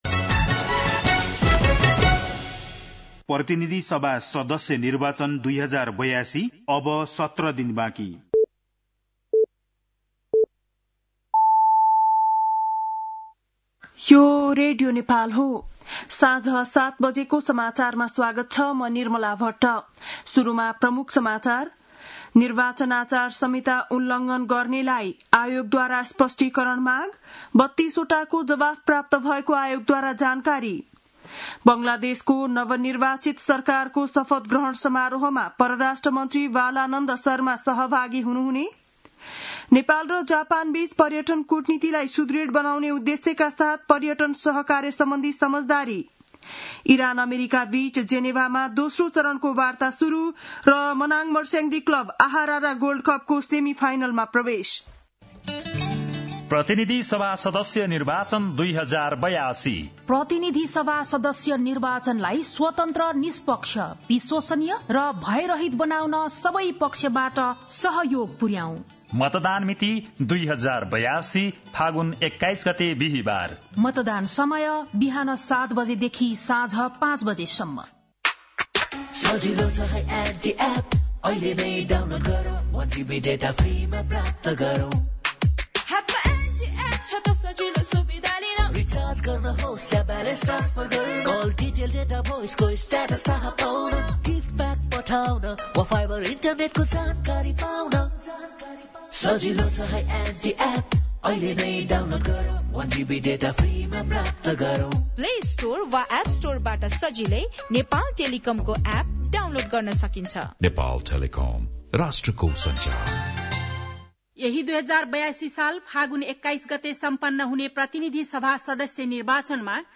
बेलुकी ७ बजेको नेपाली समाचार : ४ फागुन , २०८२
7-pm-nepali-news-11-04.mp3